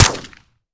weap_mike9a3_sup_plr_01.ogg